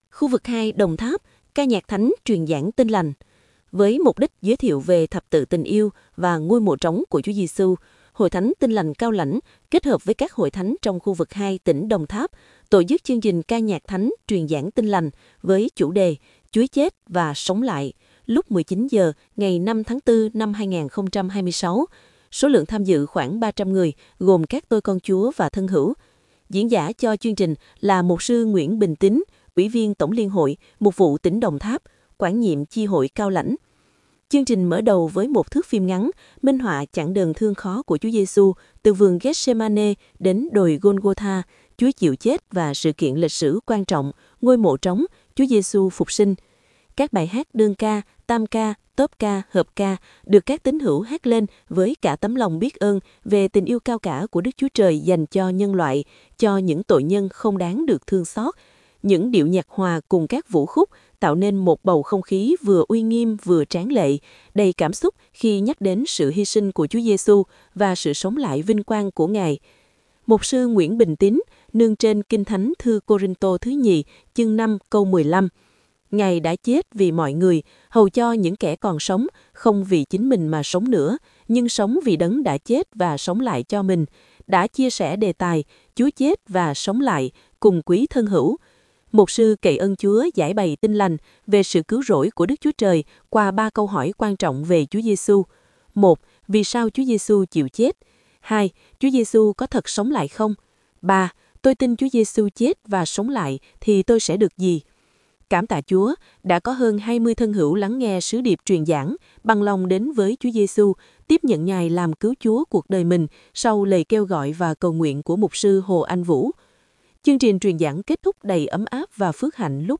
Các bài hát đơn ca, tam ca, tốp ca, hợp ca được các tín hữu hát lên với cả tấm lòng biết ơn về tình yêu cao cả của Đức Chúa Trời dành cho nhân loại, cho những tội nhân không đáng được thương xót. Những điệu nhạc hòa cùng các vũ khúc tạo nên một bầu không khí vừa uy nghiêm vừa tráng lệ, đầy cảm xúc khi nhắc đến sự hy sinh của Chúa Giê-xu và sự sống lại vinh quang của Ngài.
Hợp ca Ban Truyền giáo HT Cao Lãnh